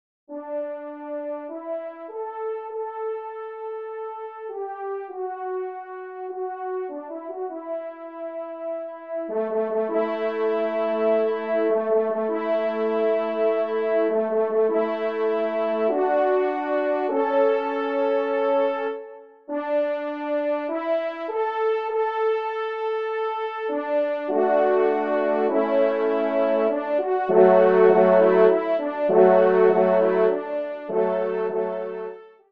Genre :  Divertissement pour Trompes ou Cors & Orgue
Pupitre 3° Trompe